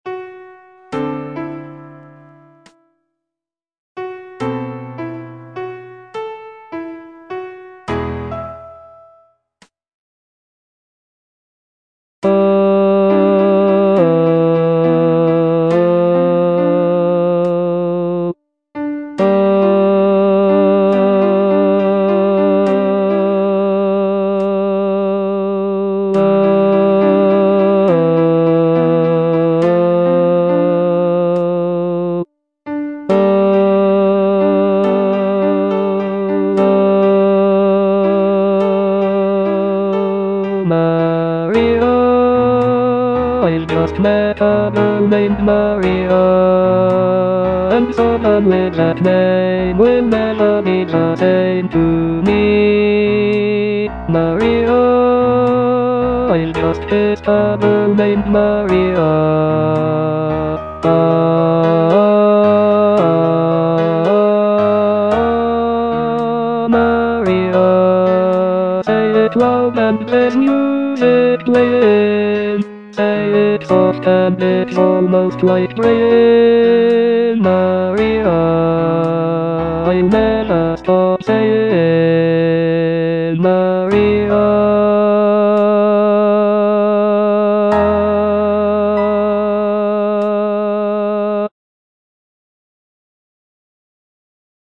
bass I) (Voice with metronome
" arranged for a choral setting.